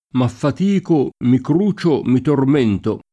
cruciare v.; crucio [kro], ‑ci — fut. crucerò [kru©er0+] (raro, alla lat., crucierò [id.]) — latinismo per «tormentare»: m’affatico, mi crucio, mi tormento [